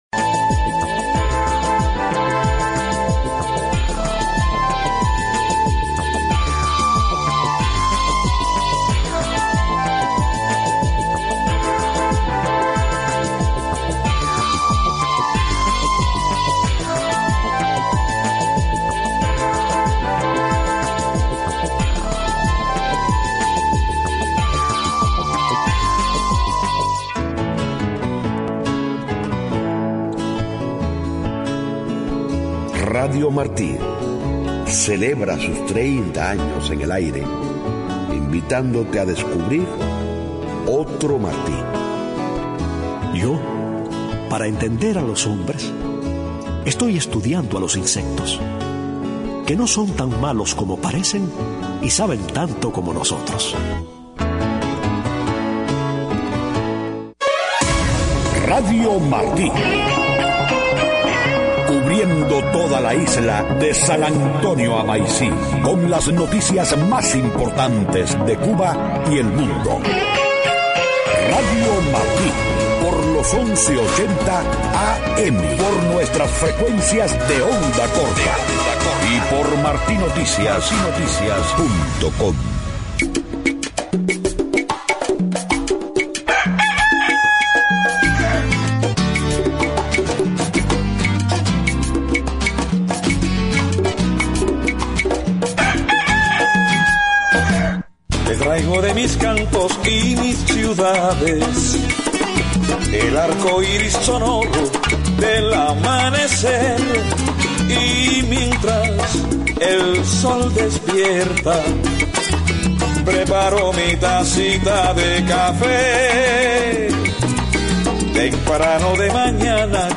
7:00 a.m Noticias: Damas de Blanco se organizan con activistas de la sociedad civil cubana para participar en Cumbre de las Américas. El presidente Obama conversará hoy en la Casa Blanca con la canciller alemana, Ángela Merkel, para tratar el conflicto en Ucrania. Crisis de escasez de papel en Venezuela pone en peligro tiradas de los periódicos.